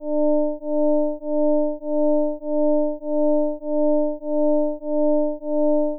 虚部(左イヤホン)にはsin(2πt/1.2)